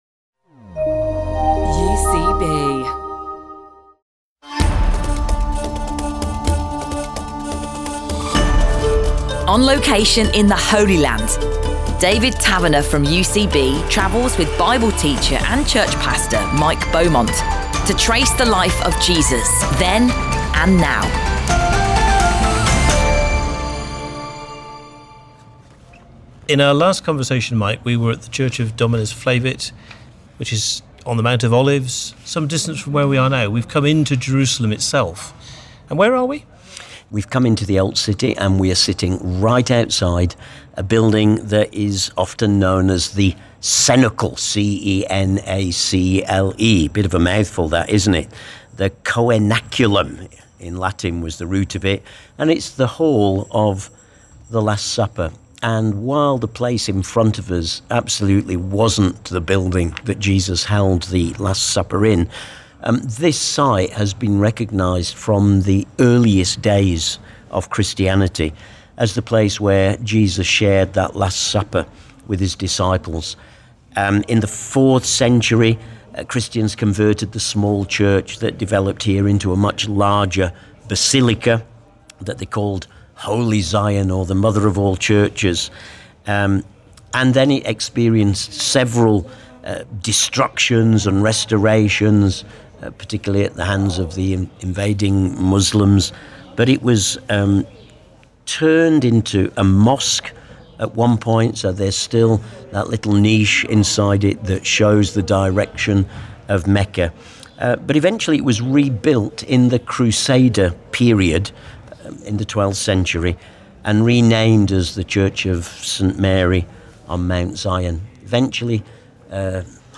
From The Upper Room in Jerusalem.